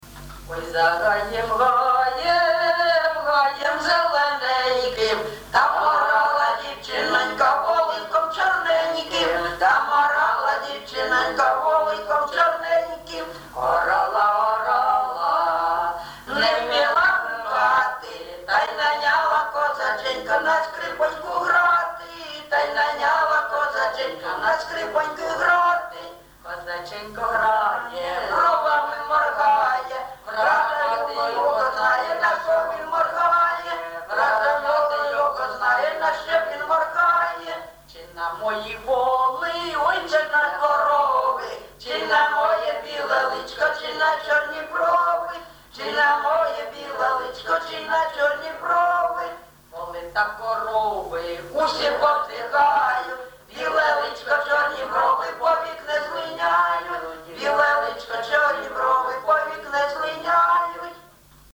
ЖанрПісні з особистого та родинного життя, Жартівливі
Місце записум. Єнакієве, Горлівський район, Донецька обл., Україна, Слобожанщина